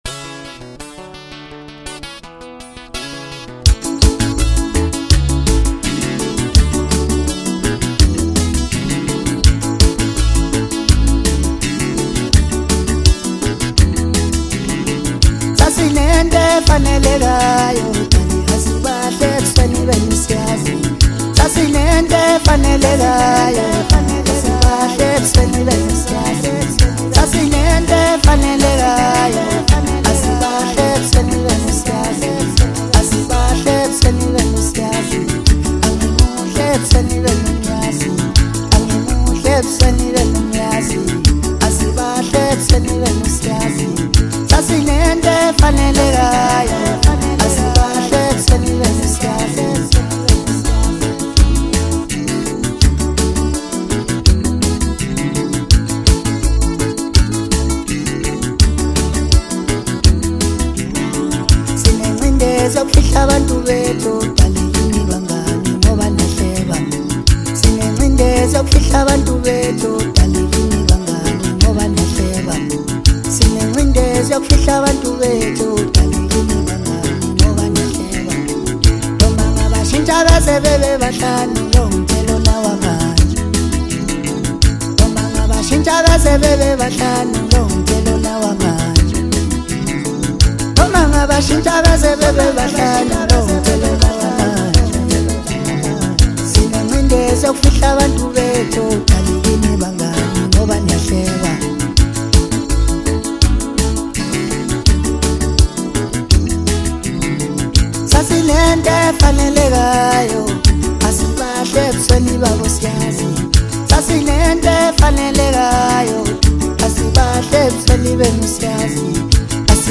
Zulu Maskandi